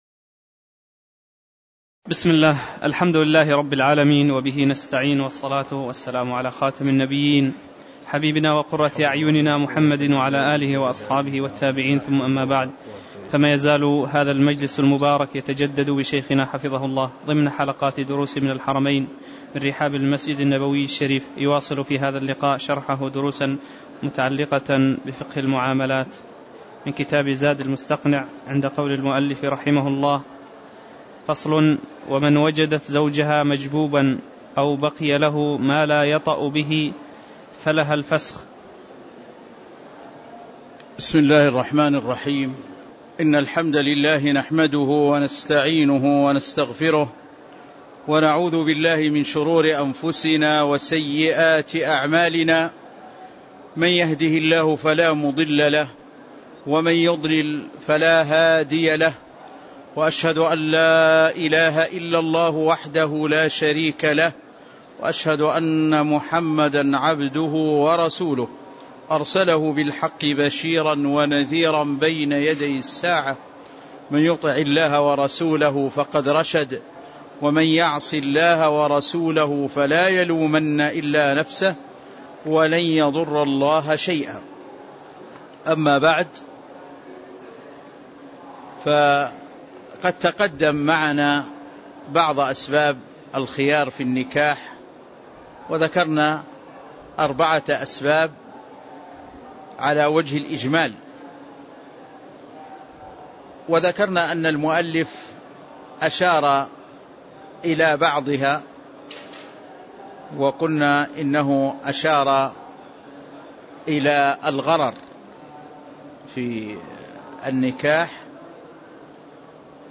تاريخ النشر ١٢ جمادى الأولى ١٤٣٧ هـ المكان: المسجد النبوي الشيخ